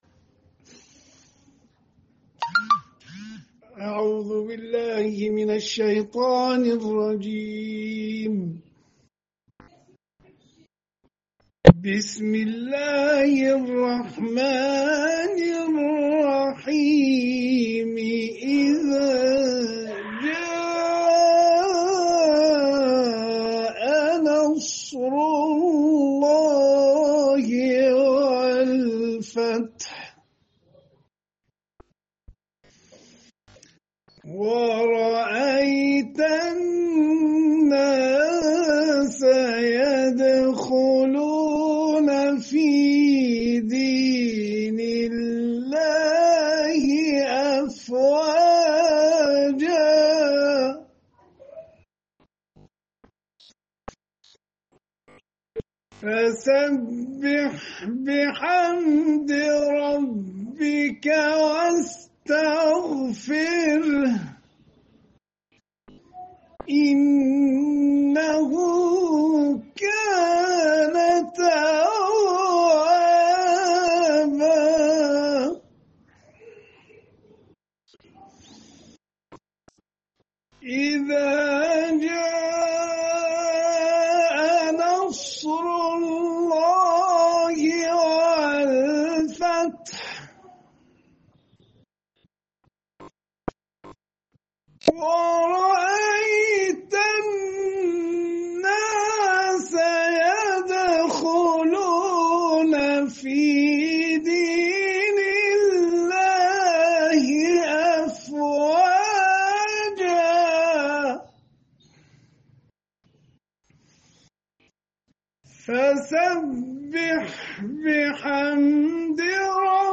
Сураи Наср бо Тиловати қории миcрӣ + садо
Садои тиловат